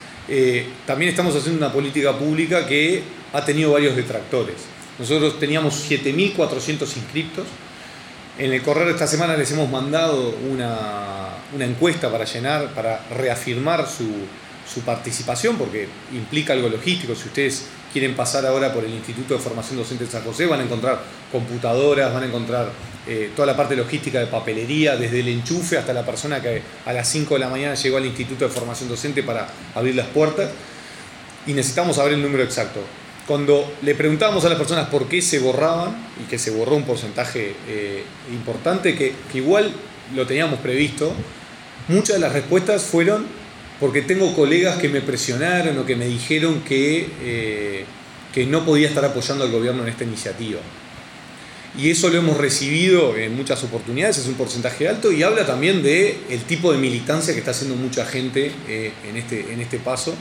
Gonzalo Baroni, director nacional de Educación.